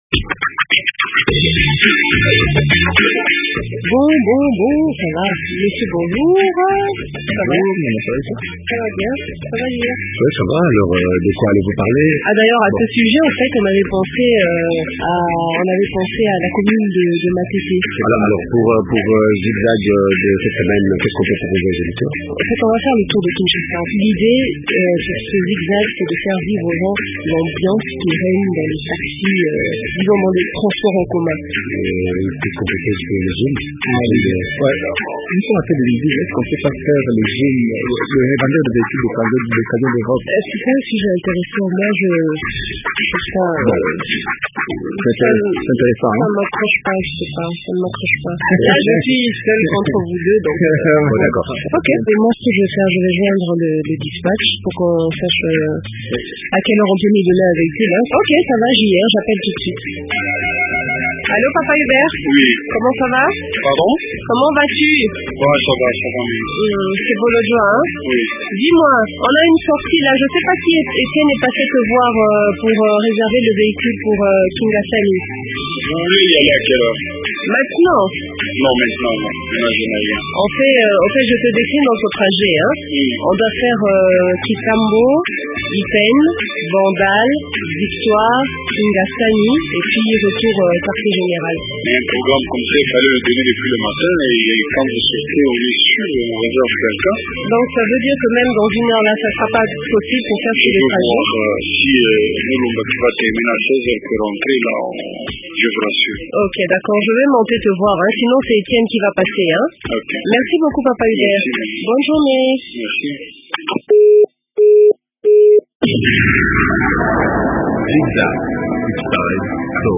A bord des transports en commun de Kinshasa, Zig Zag vous amène du centre ville au quartier UPN dans la commune de Ngaliema.